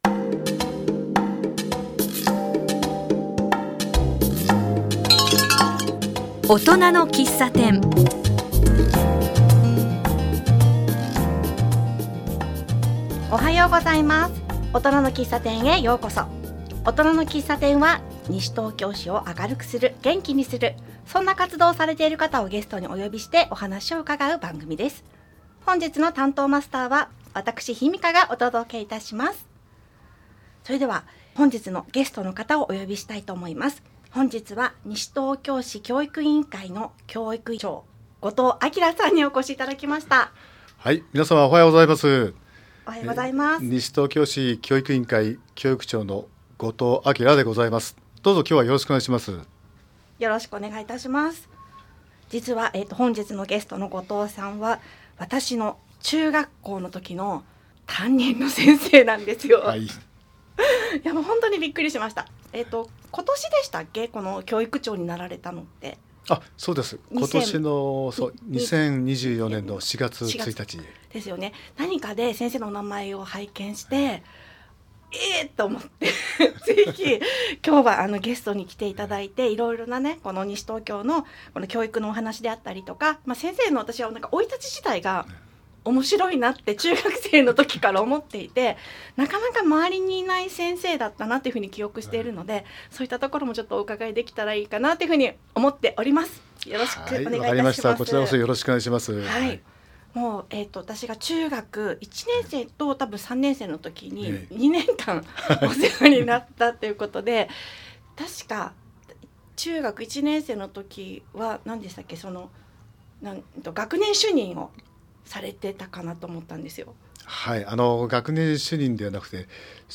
教育委員会の仕事についてお話を伺う機会をいただき、後藤教育員長にお越しいただきました。学校教育だけではなく、市民の皆さんにとても関わりのある役割があることが知ることができました。